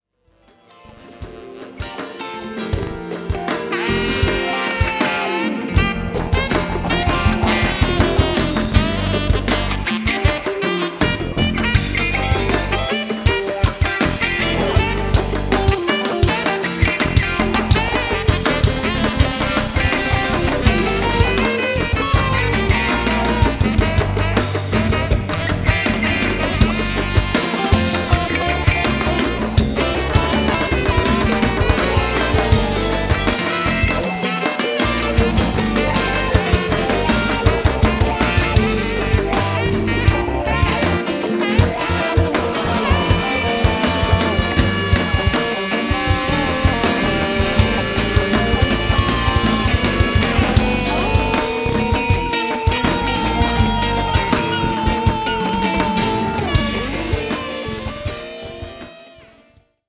In short, it grooves as it moves.